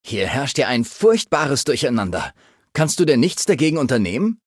Datei:Maleadult01default convandale hello 0001b03c.ogg
Fallout 3: Audiodialoge